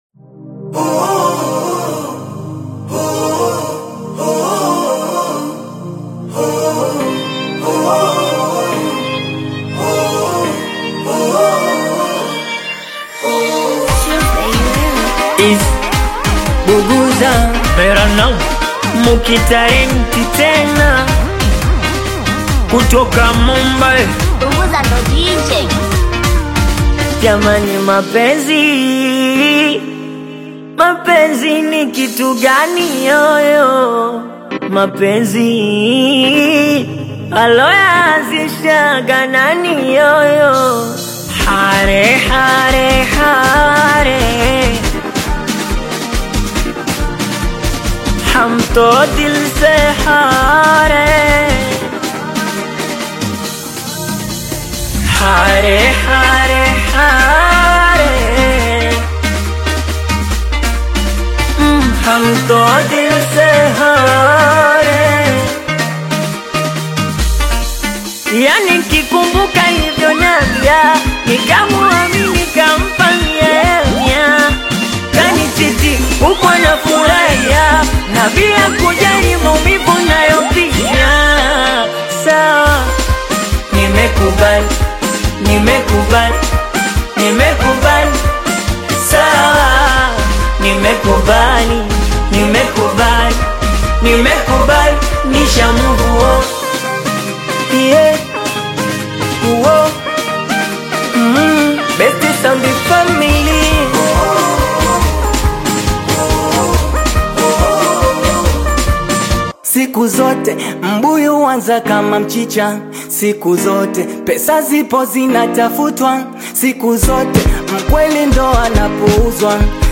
high-energy Singeli remix single